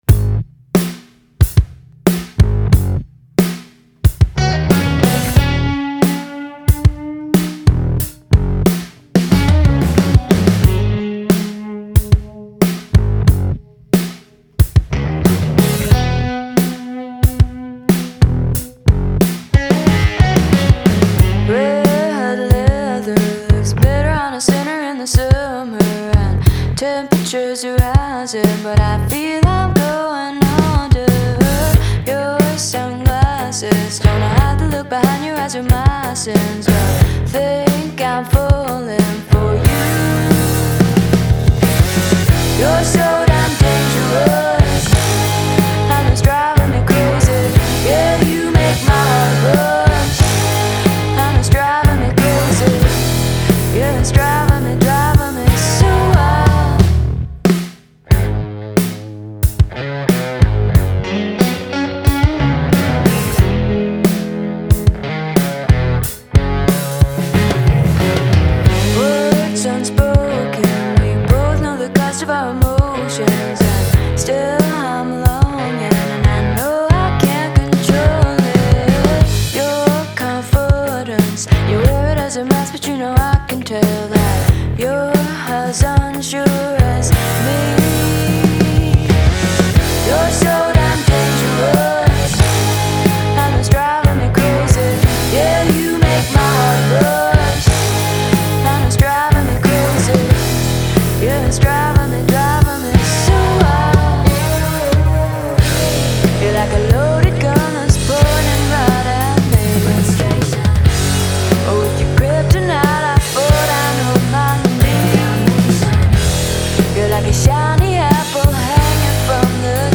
BPM91-91
Audio QualityPerfect (High Quality)
Rock song for StepMania, ITGmania, Project Outfox
Full Length Song (not arcade length cut)